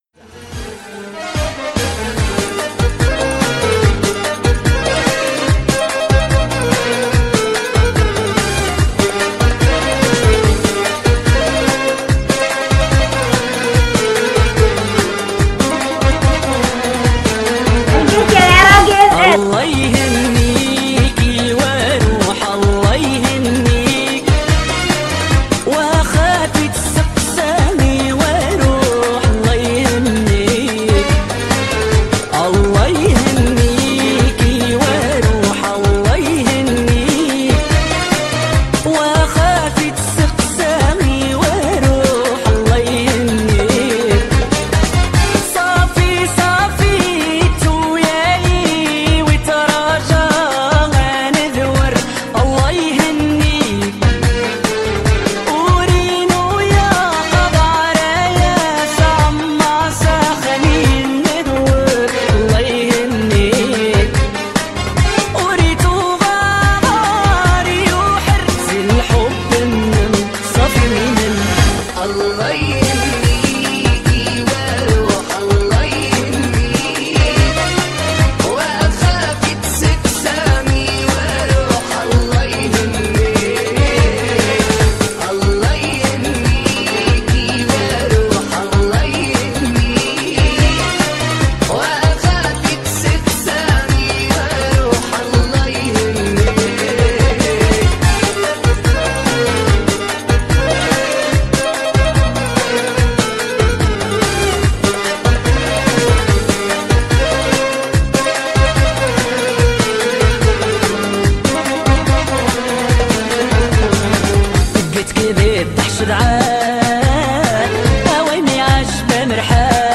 اغاني مغربيه استكنان